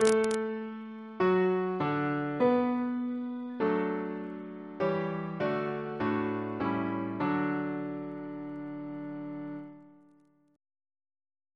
Single chant in D Composer: Oxford Chant Reference psalters: H1940: 642 674; H1982: S48